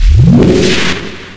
se_power1.wav